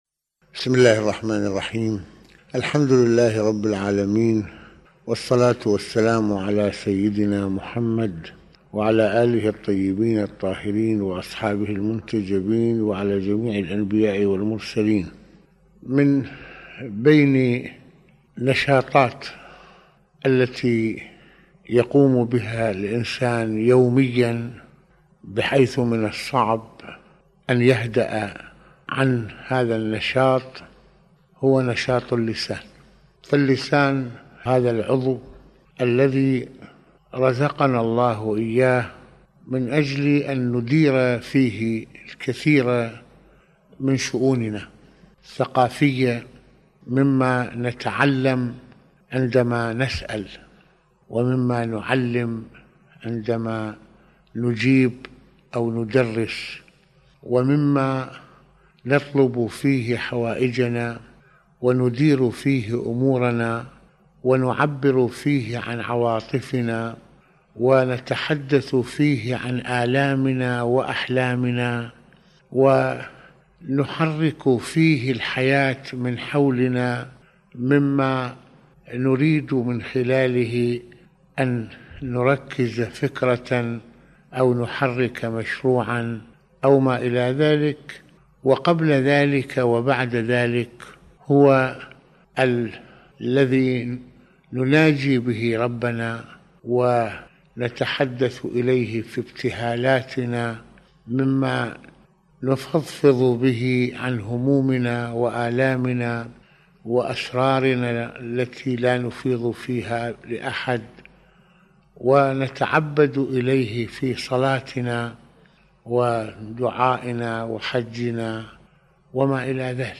حديث السحر: اللسان ومسؤولية حفظه | محاضرات رمضانية
- يتحدّث العلامة المرجع السيد محمد حسين فضل الله(رض) في هذه المحاضرة عن نشاط اللسان، هو هبة من الله لإدارة شؤوننا العامة والخاصة، فهو أداة التعبير وأداة العلم، وهو ما نحرك به الحياة من حولنا،وهو ما نتحدث به مع ربنا ونتعبد به، فالله يريد لهذا اللسان أن يكون نعمة للإنسان وللعلاقات مع الآخرين والحياة..